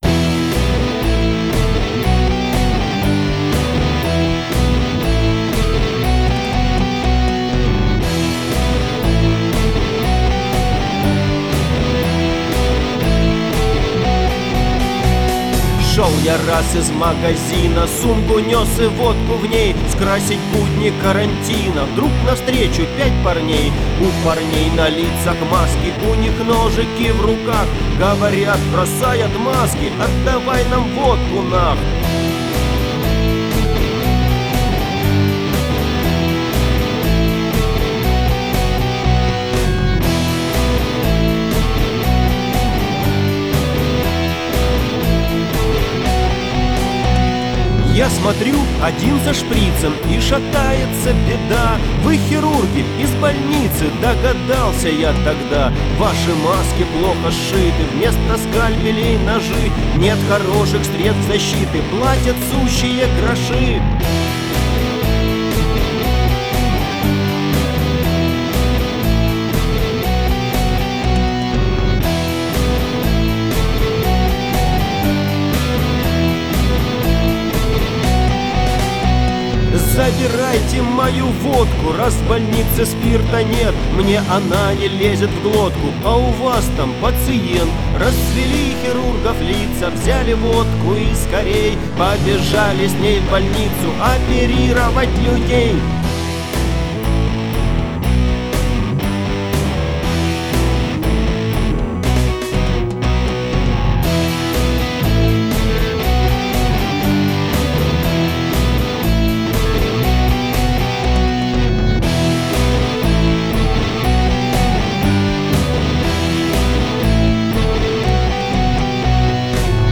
политический панк-рок